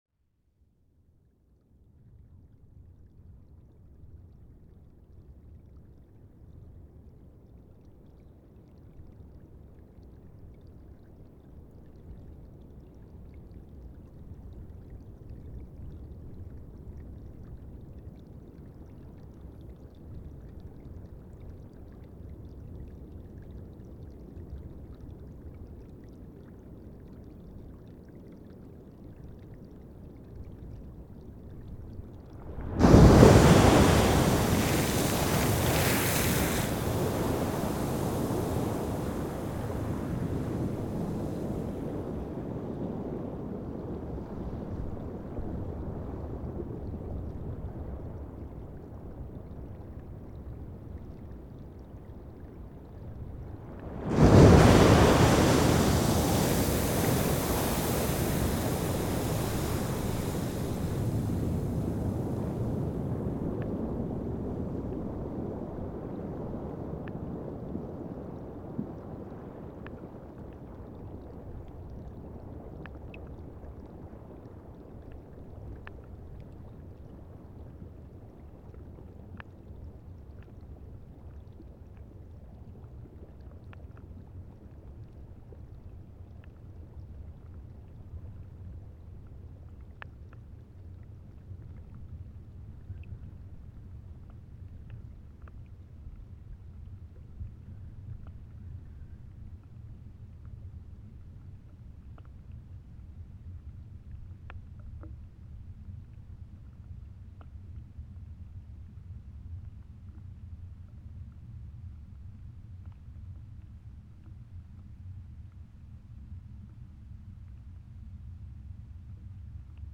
Posted in Náttúra, tagged Aquarian H2a-XLR, Blesi, Geysir, Great Geyser, Haukadalur, Hot spring, ORTF, Sennheiser MKH8040, Sound Devices 788, Strokkur on 4.5.2014| Leave a Comment »
The plan was to record the sound in Geysir and other hot springs in the area with hydrophones.
Anyway, I got some recordings that I mixed together in one 20 minutes session. It started with eruption in hot spring named Strokkur .
Then Strokkur erupted again before we dived into the “blue side” of the hot spring Blesi. After several minutes Strokkur erupted again and we dived into the ”deep side” of Blesi. Then we went to the surface and listened to two eruptions on Strokkur in a fellowship with two tourists.
Var komið við á stöðum eins og á Geysi þar sem hljóðnemar voru brúkaðir bæði ofan jarðar og neðan.